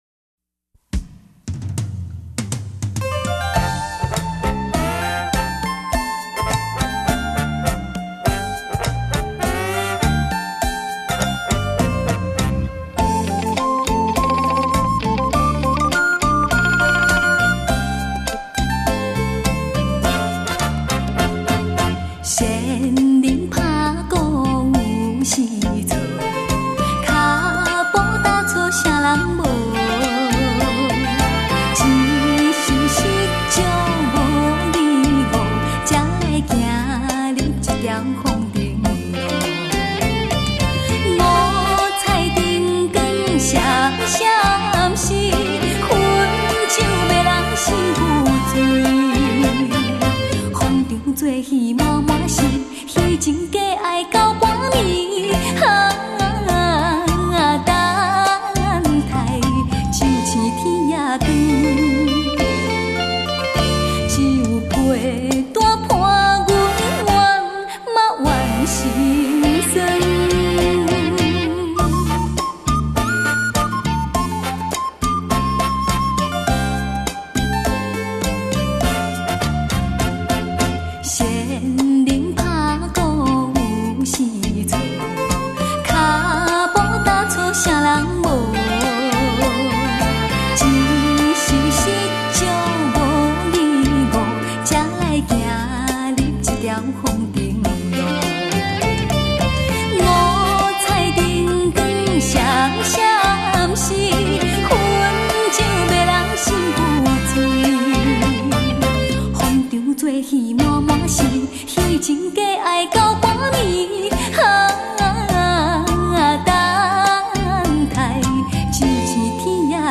太好听的绝色女声极致音乐专辑，谢谢楼主的精彩奉献！